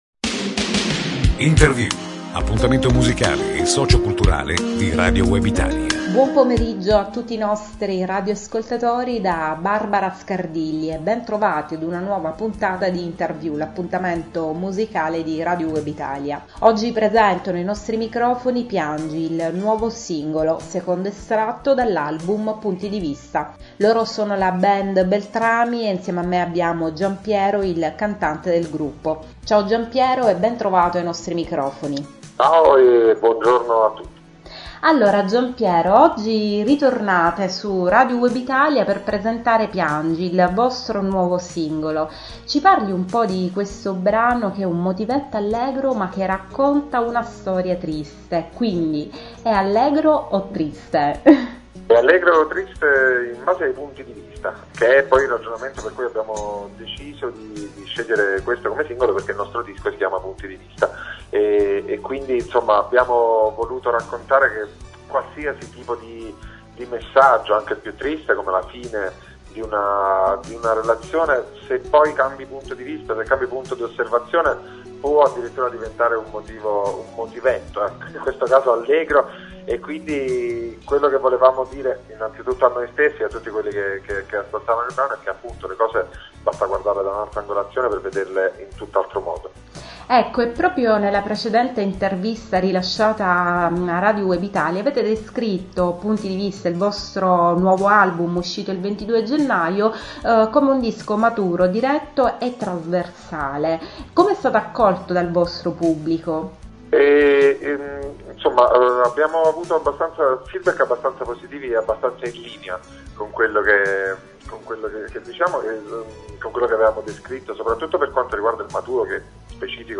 Intervista alla band Beltrami per ‘Piangi’, il nuovo singolo e video